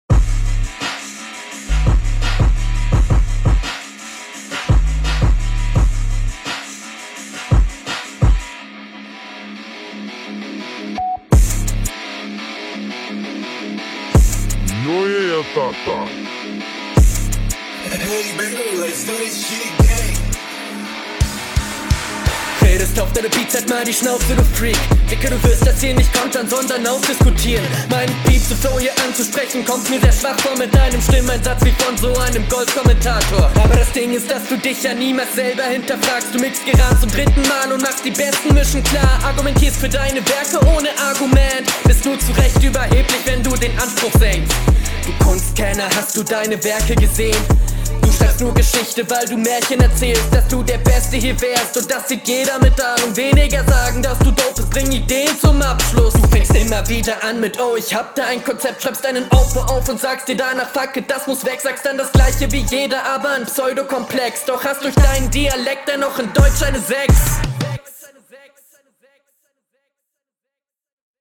schrecklicher beat, 60 jahre intro auch nervig, aber flow und stimmeinsatz deutlich cooler, auch sehr …